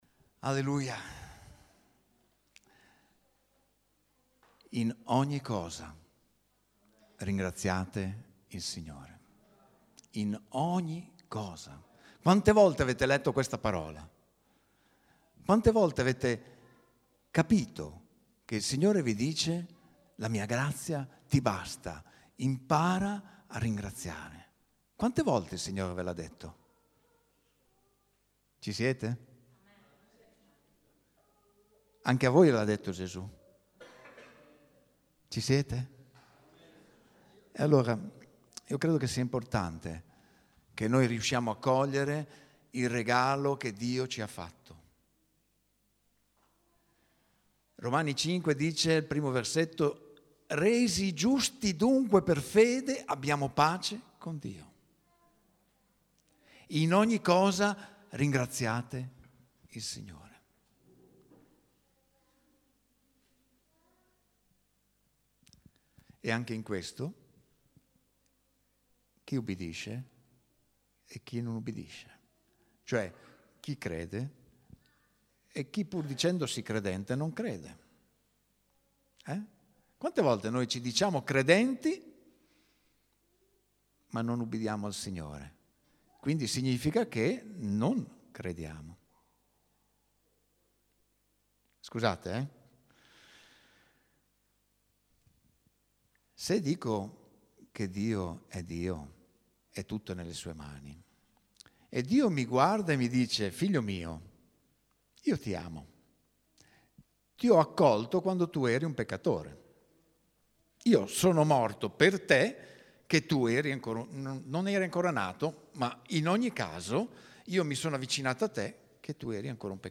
L’esperienza che porta speranza, ringraziando in ogni cosa il Signore.. 0:00 ( Clicca qui se vuoi scaricare il file mp3 ) ‹ La semina TDR 2014 Numero 8 › Pubblicato in Messaggio domenicale